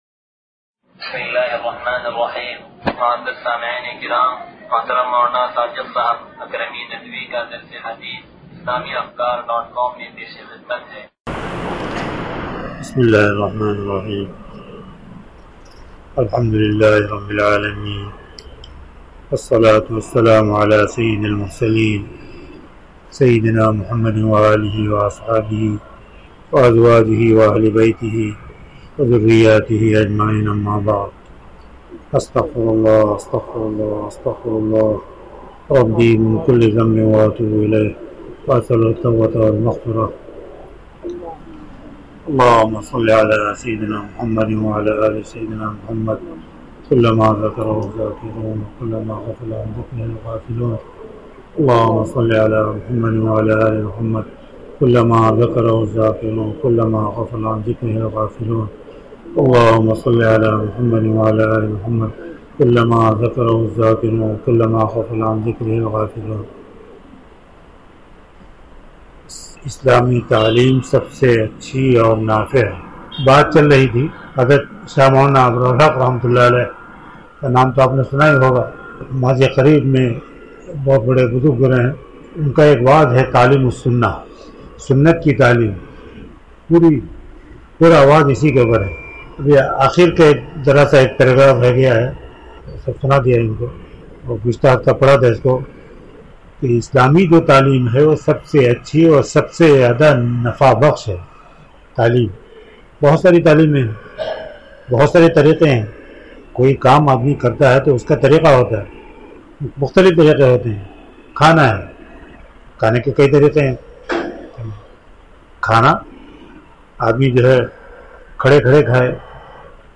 درس حدیث نمبر 0393